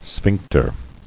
[sphinc·ter]